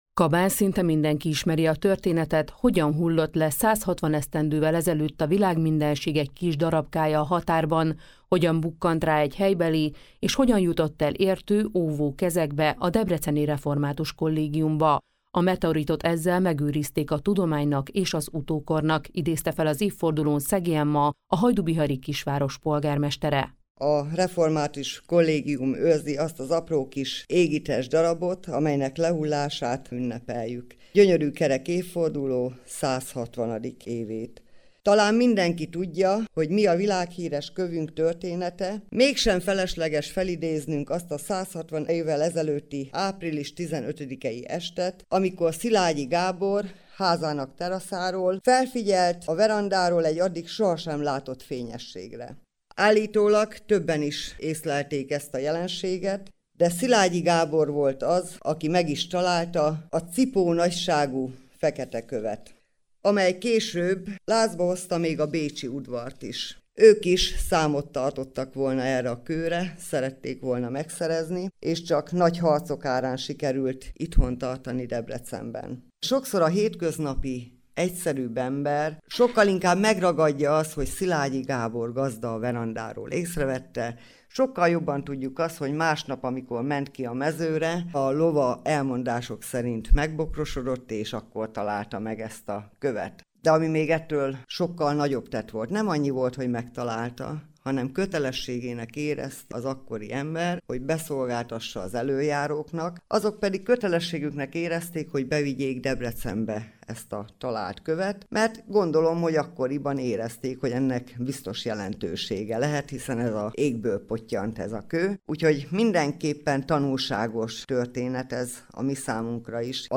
kaba-meteorit-varosnap.mp3